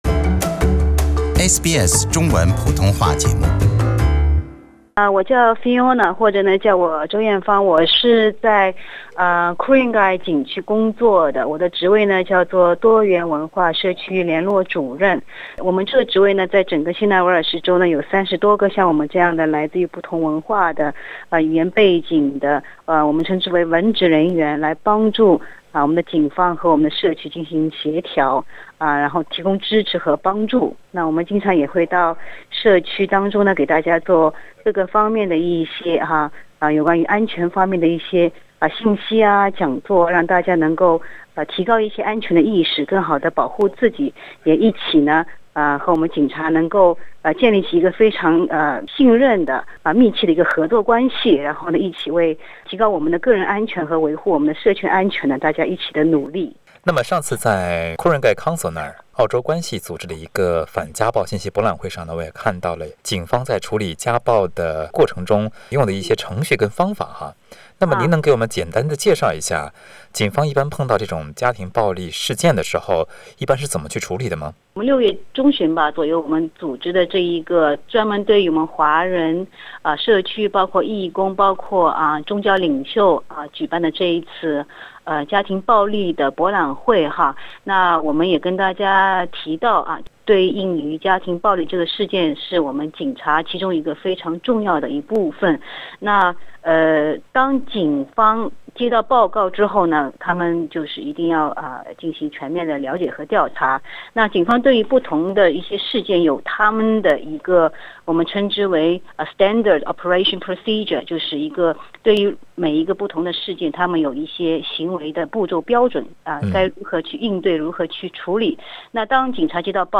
请您点击收听详细的采访内容。